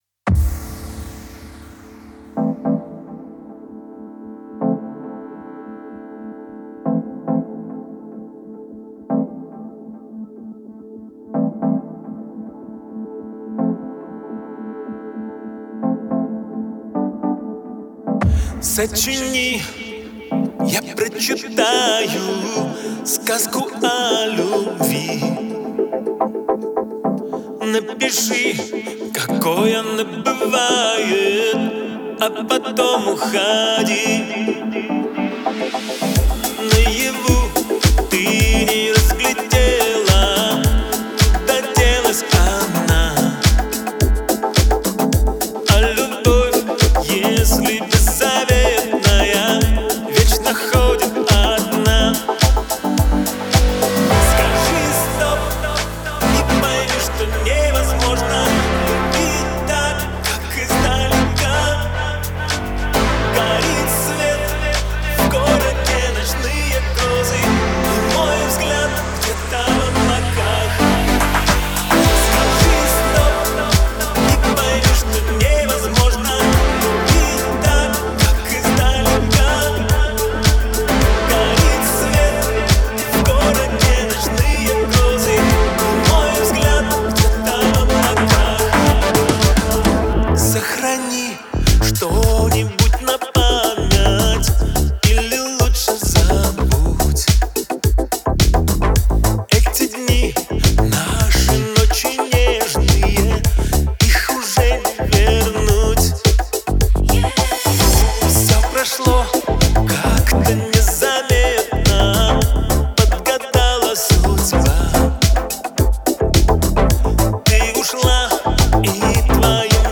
Трек размещён в разделе Поп / 2022 / Казахская музыка.